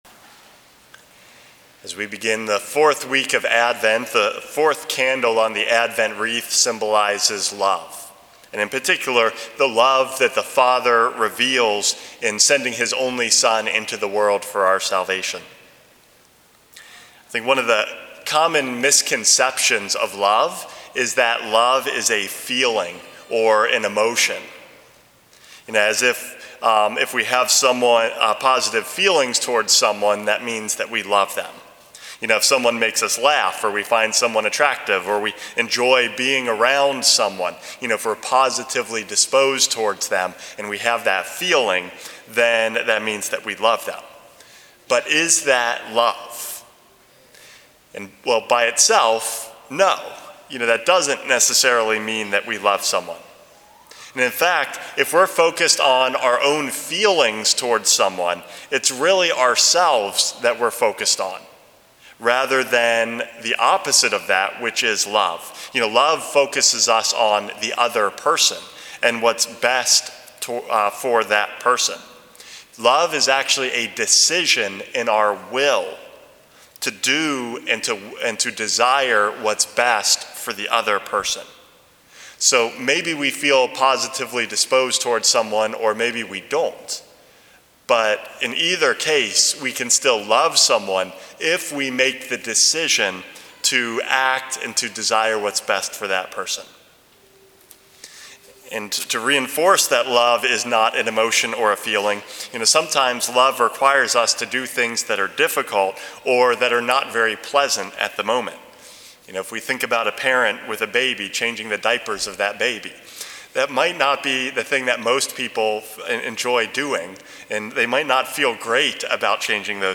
Homily #430 - The Forth Candle